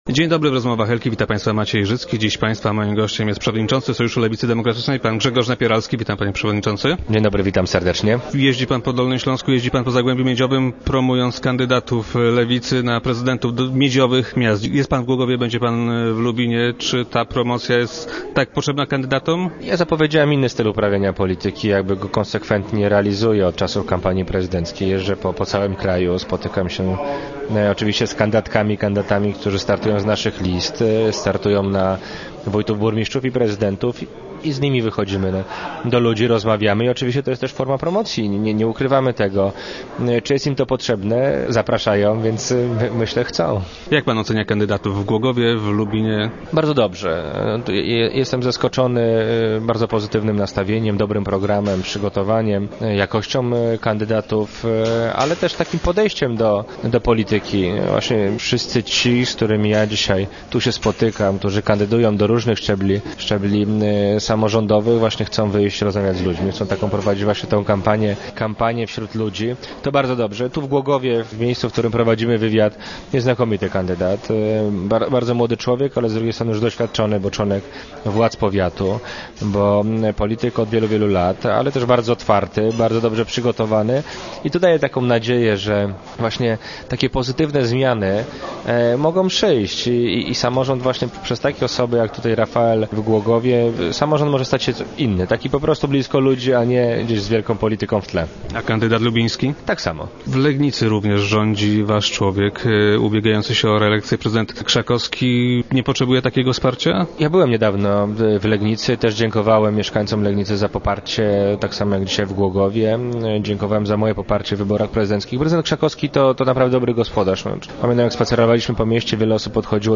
Przewodniczący Sojuszu Lewicy Demokratycznej był gościem czwartkowych Rozmów Elki.